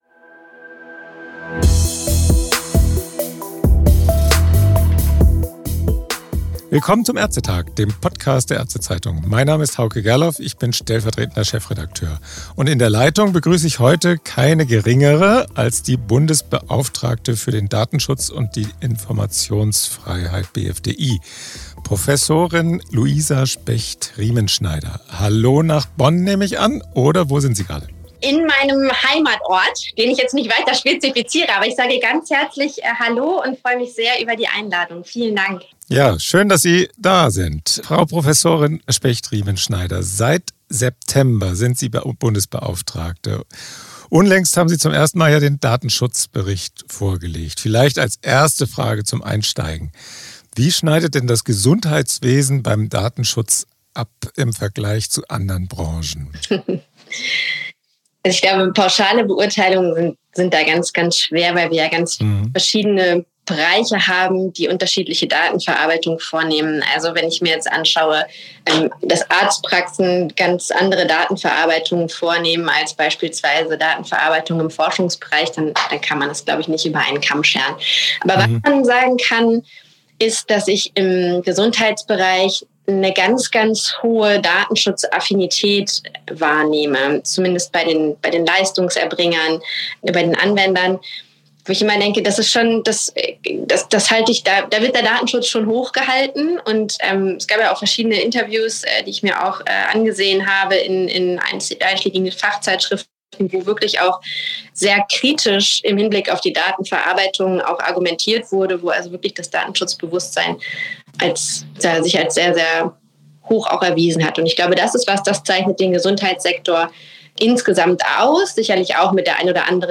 Im „ÄrzteTag“-Podcast erläutert sie, wo die größten Probleme liegen.